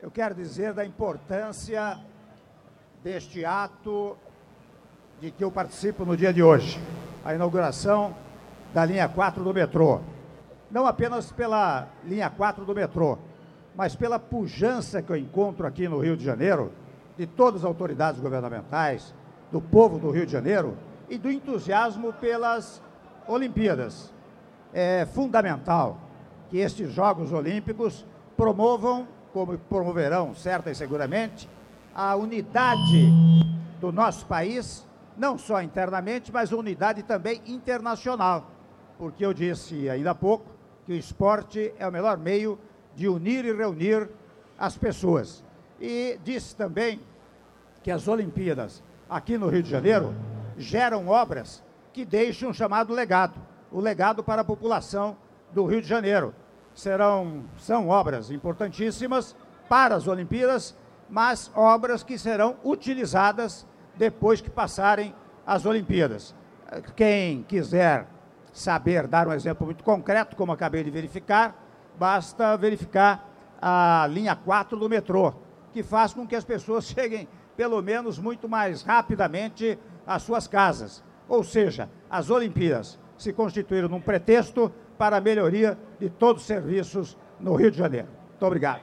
Áudio da declaração à imprensa do presidente da República em exercício, Michel Temer, após cerimônia de Inauguração da Linha 4 do Metrô - Rio de Janeiro-RJ(01min28s)